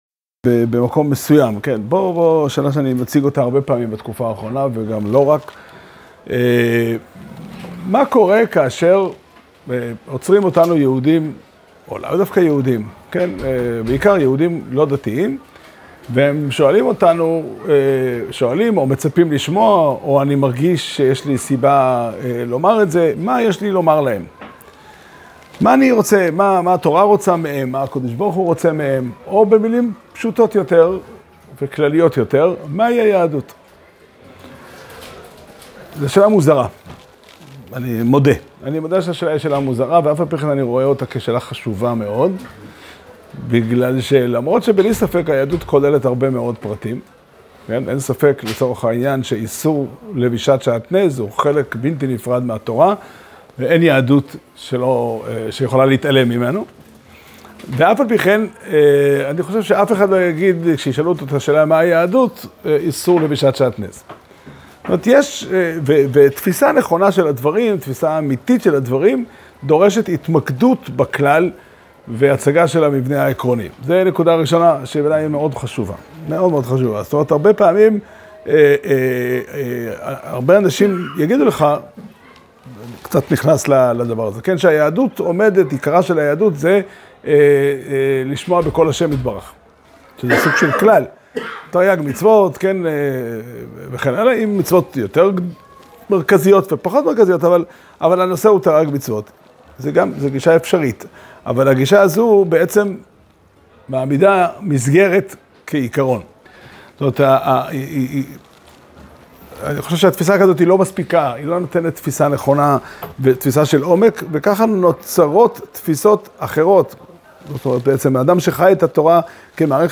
שיעור שנמסר בבית המדרש פתחי עולם בתאריך ט"ו טבת תשפ"ה